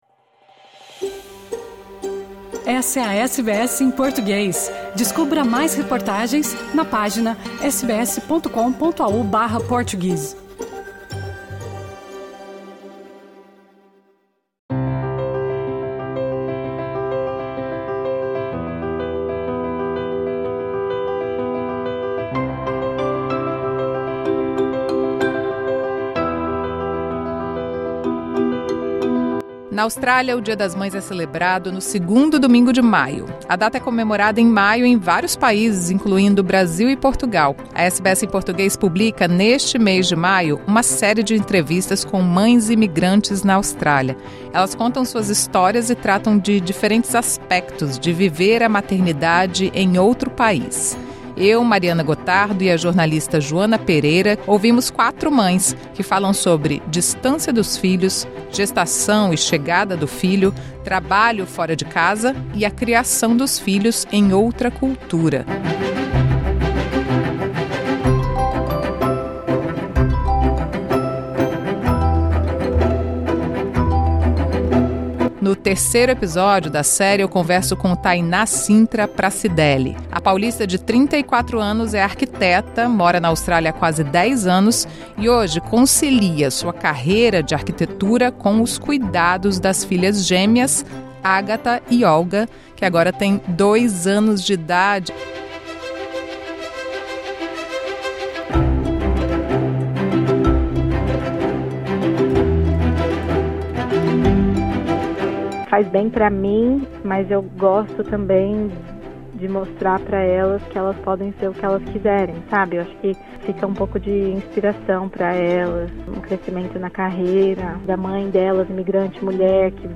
Esta entrevista faz parte de uma série da SBS em português com mães imigrantes na Austrália.